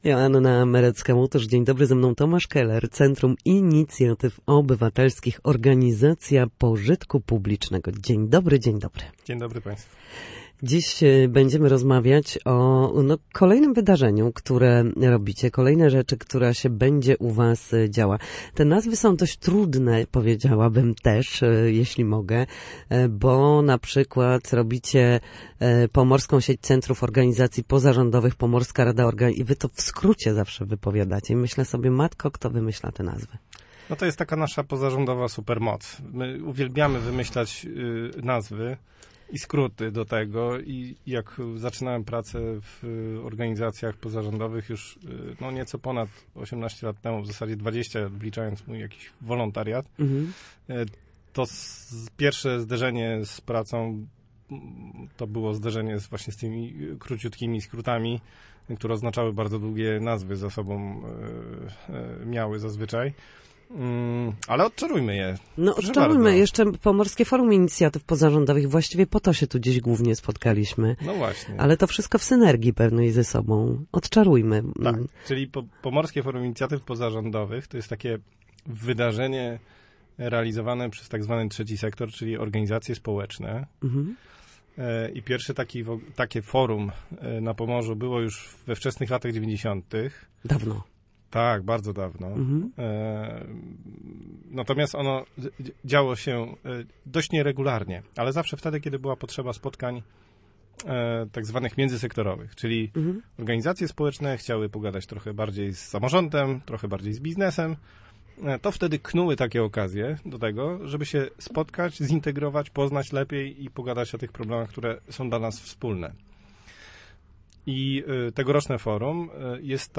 Na naszej antenie mówił o dużym, nadchodzącym wydarzeniu